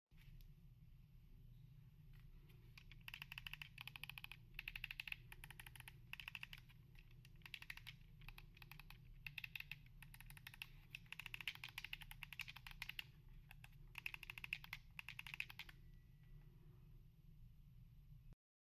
Here’s the sound of our tactical buttons for Xbox controllers. Crispy sound with instant reaction.